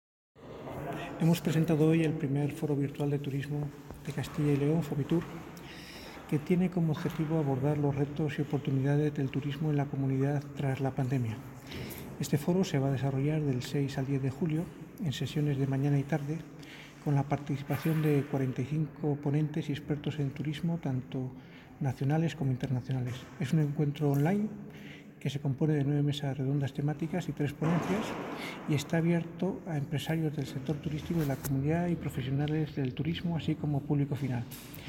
Intervención del viceconsejero.